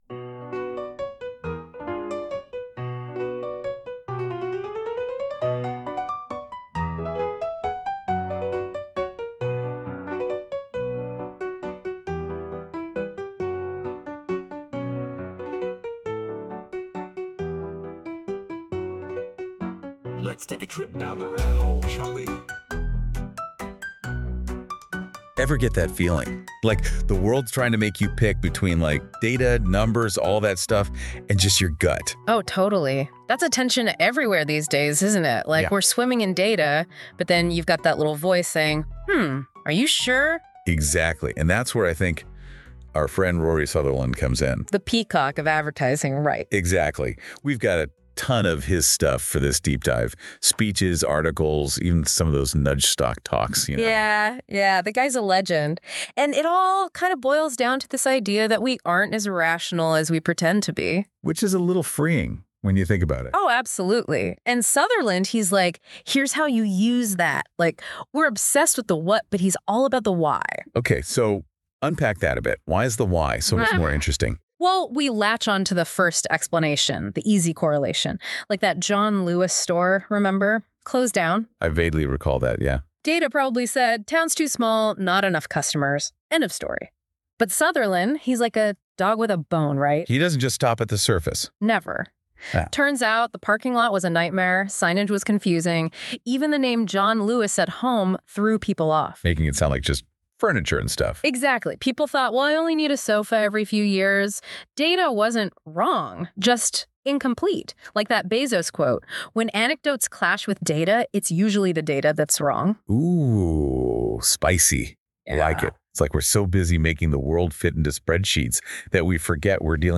An unapologetically AI podcast with real human thoughts captured, curated, and shared through AI.